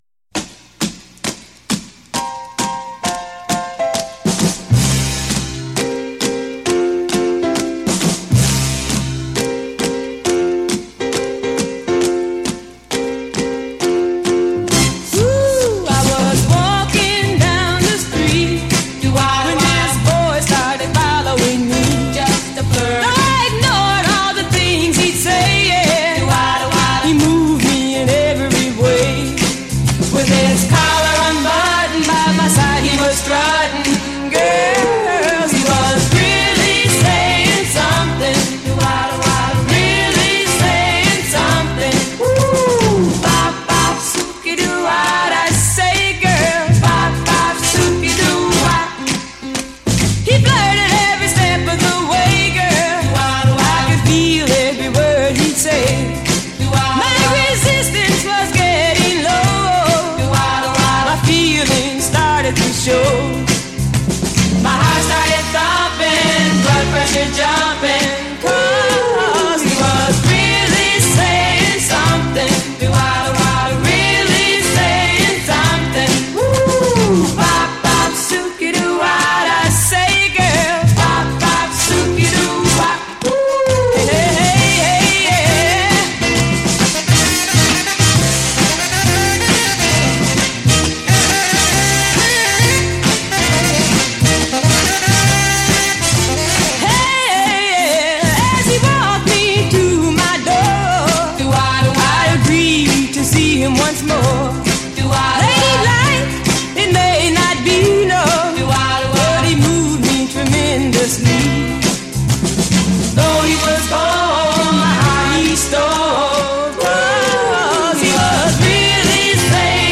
Swingin’ 60s Soul a go-go.